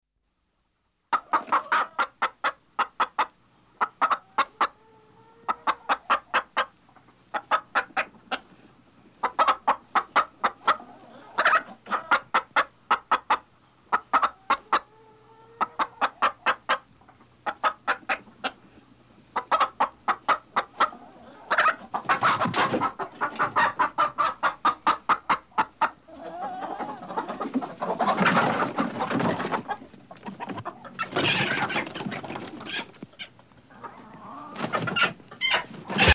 Рингтоны » звуки животных » Курица квохчет